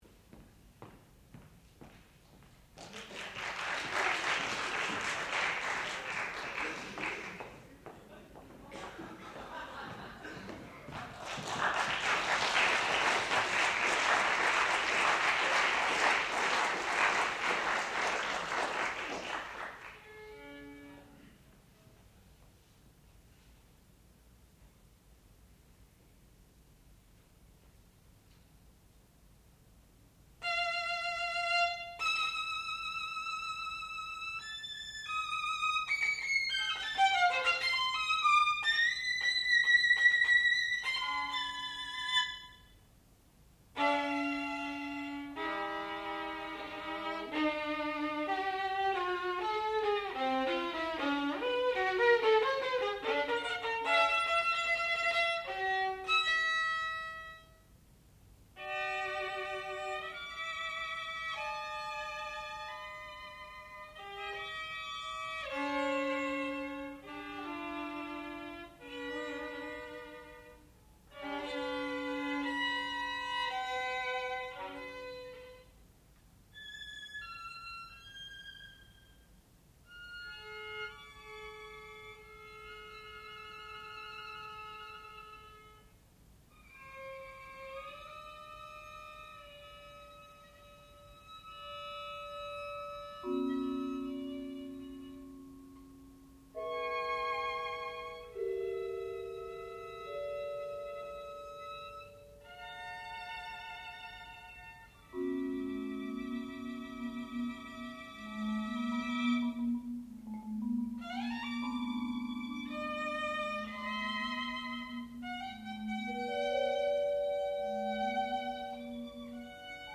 sound recording-musical
classical music
Graduate Recital